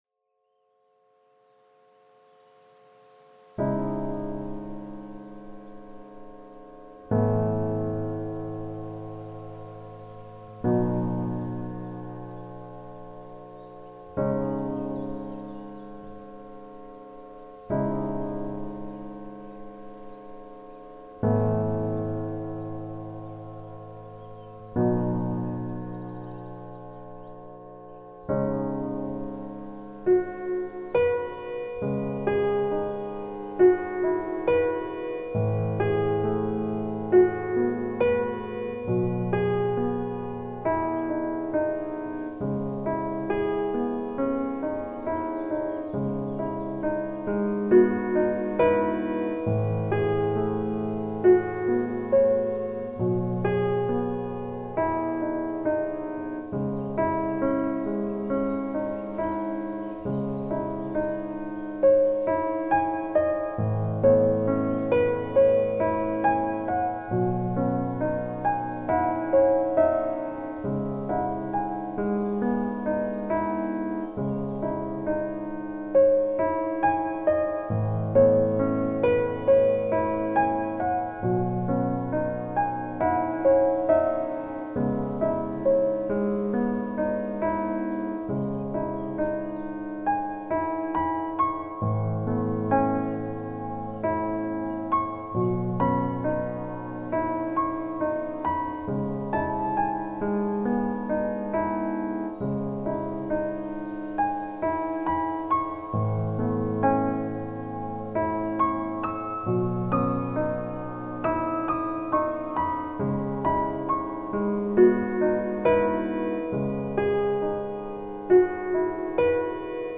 • -1) Quatre fréquences spécifiques, sélectionnées en résonance avec les organes ciblés, pour stimuler leurs fonctions énergétiques et vibratoires.
• -2) Un accompagnement au piano accordé en 432 Hz (titre : Yūgen), fréquence naturelle réputée pour favoriser l’harmonie, la détente et l’alignement intérieur.
• -3) Une ambiance sonore inspirée de la nature (eau, vent, oiseaux…), qui enveloppe l’écoute dans une atmosphère apaisante et immersive.